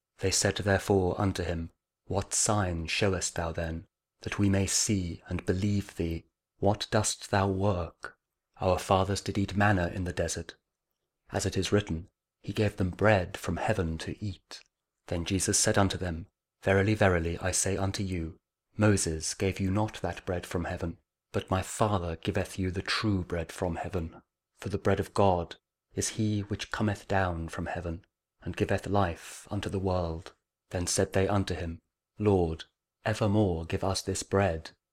John 6: 30-34 Audio Bible KJV | King James Audio Bible | King James Version | Daily Verses